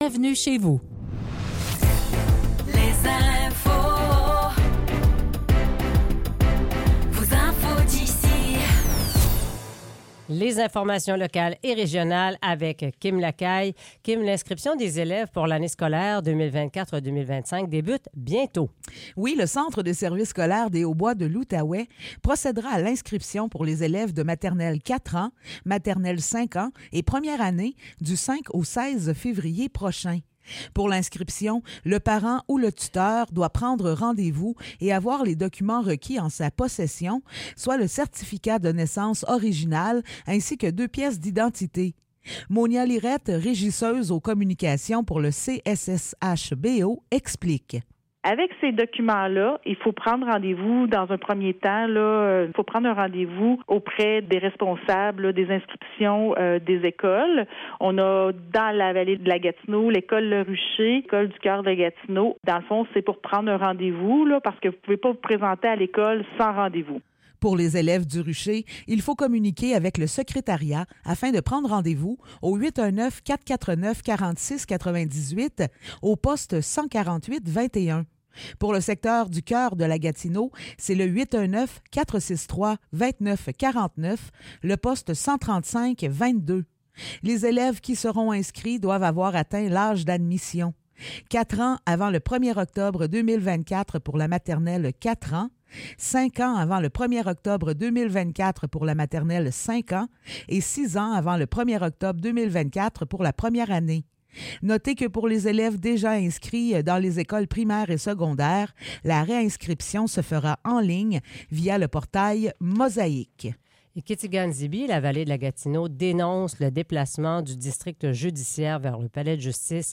Nouvelles locales - 24 janvier 2024 - 8 h